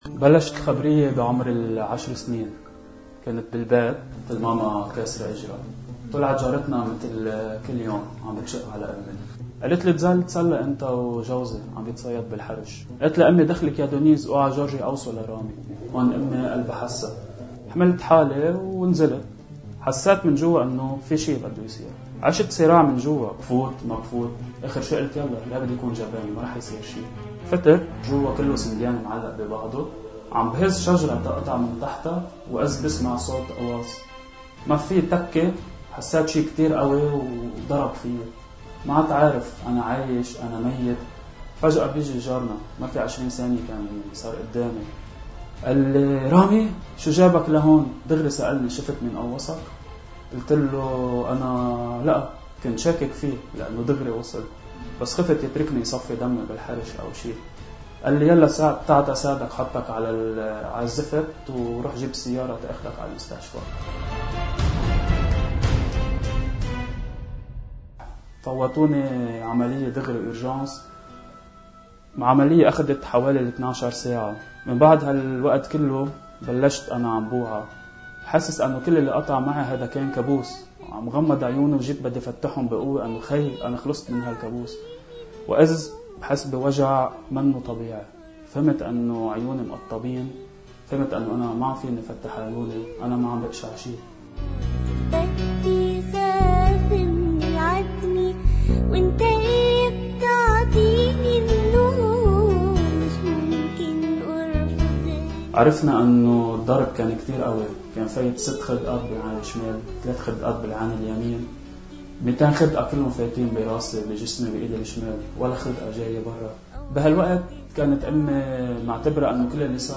شهادة